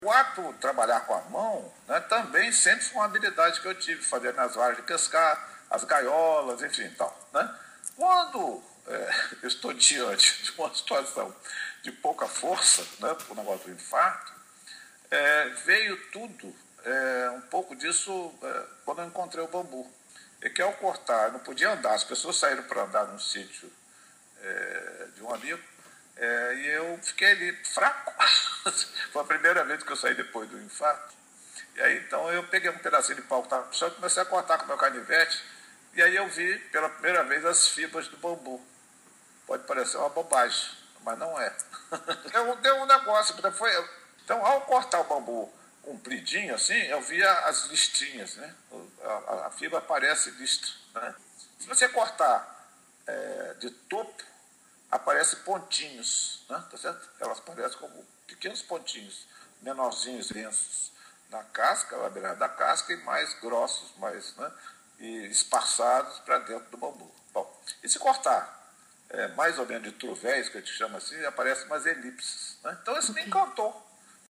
entrevista por Skype